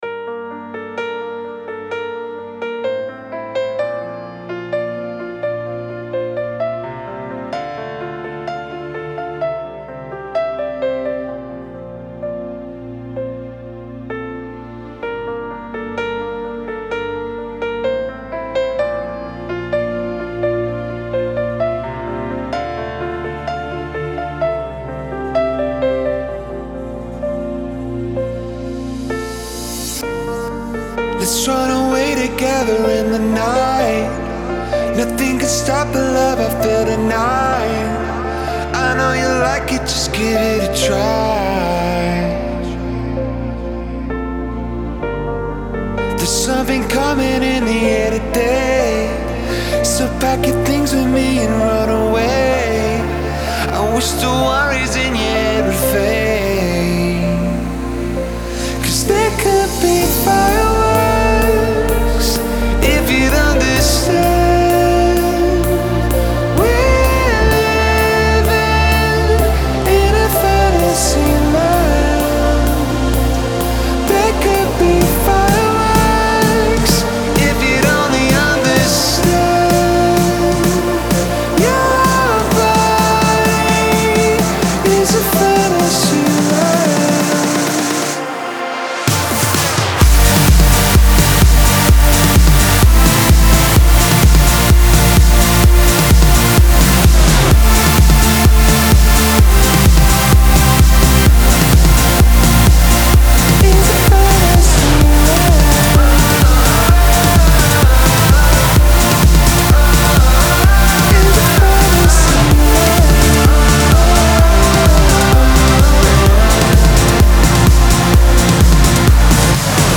Категория: Электро музыка » Транс